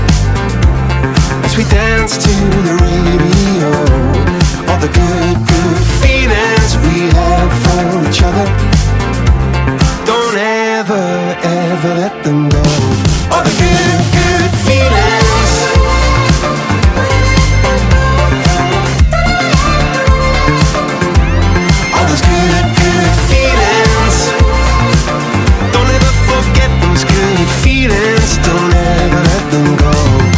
Dance pop, hymnes de stade et ballade amoureuse